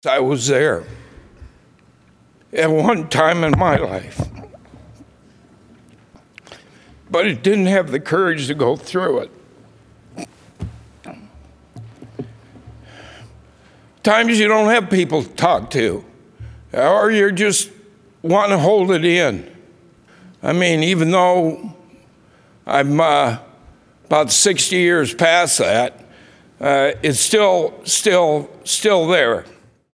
Senator Bill Dotzler, a Democrat from Waterloo, grew emotional as he thanked his colleagues for supporting the bill.